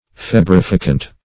Search Result for " febrifacient" : The Collaborative International Dictionary of English v.0.48: Febrifacient \Feb`ri*fa"cient\, a. [L. febris fever + faciens, p. pr. of facere to make.]